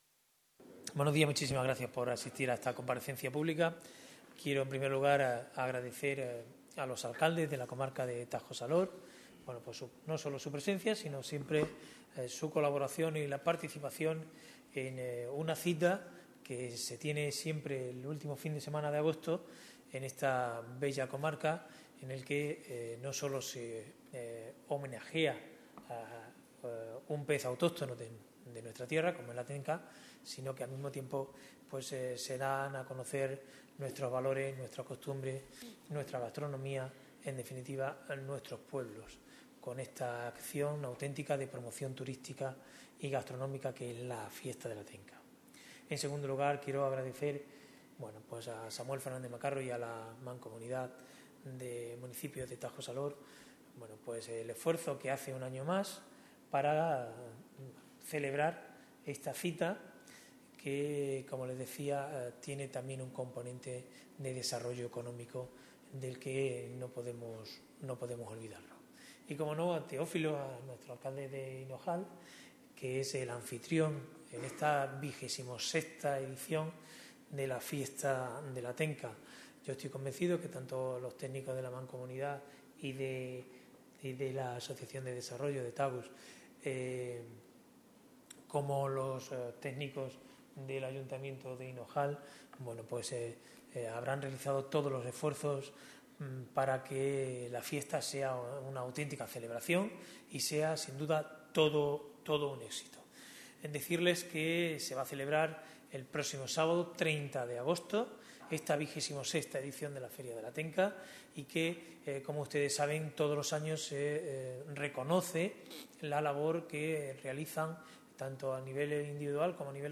CORTES DE VOZ
27/08/2014-. El presidente de la Diputación de Cáceres, Laureano León Rodríguez, acompañado por el vicepresidente de la Mancomunidad Tajo-Salor, Samuel Fernández Macarro, y por el alcalde de Hinojal, Teófilo Durán Breña, ha presentado este miércoles en rueda de prensa la XXVI edición de la Fiesta de la Tenca, que se celebrará este sábado, 30 de agosto, en la localidad cacereña de Hinojal.